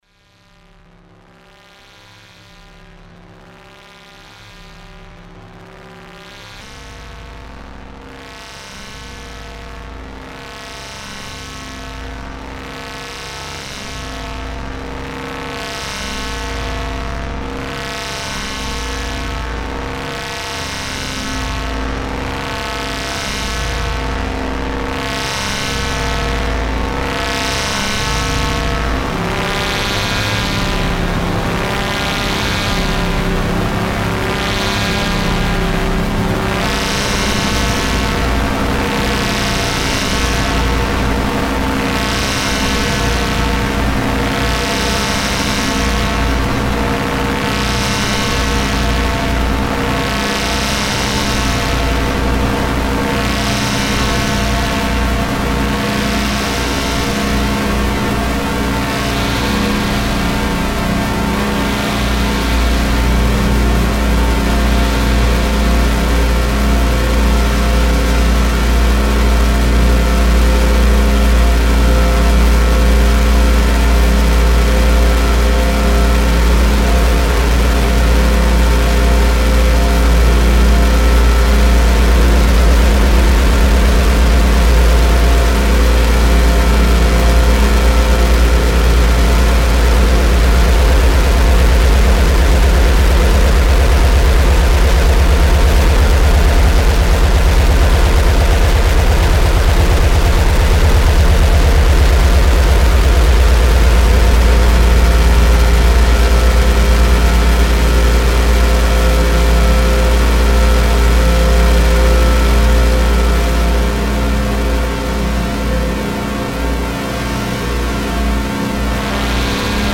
an ongoing exploration of electronic music